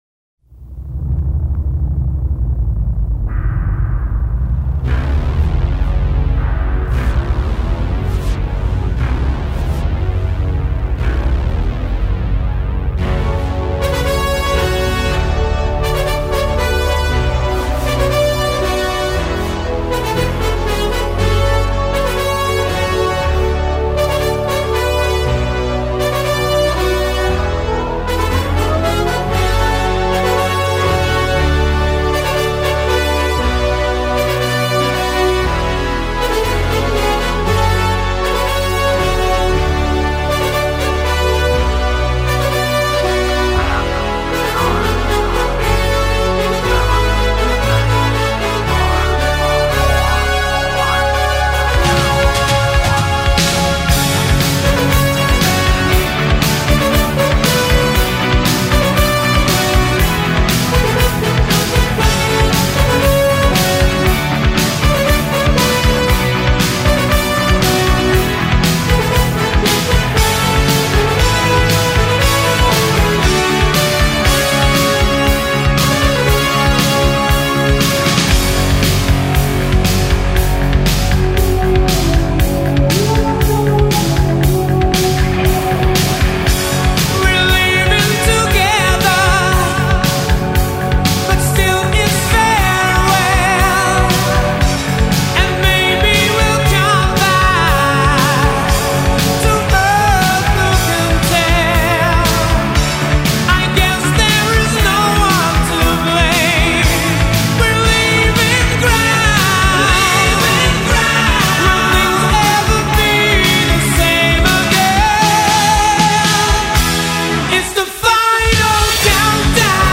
除了前奏以外還有全曲都有..
聽完了之後感覺很豪邁....
真的是氣勢磅礡且耐聽的一首歌..